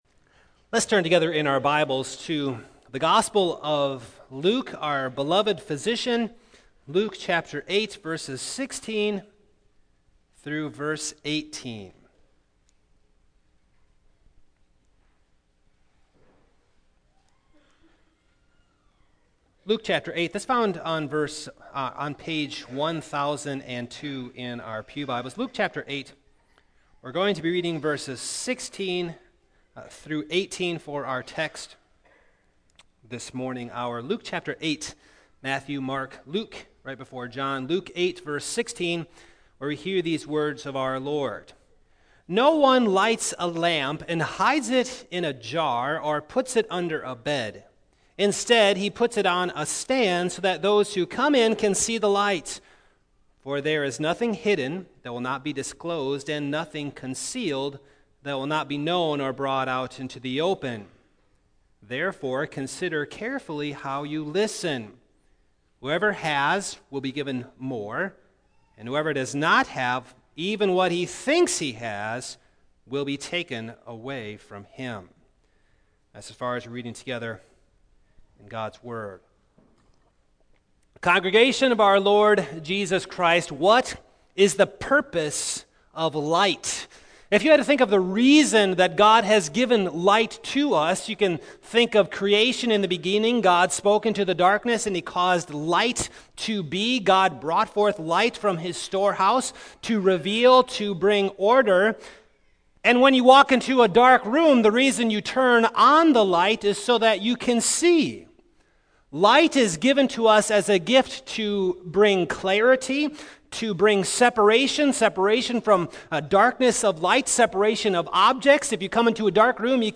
2017 A Lamp in the World Preacher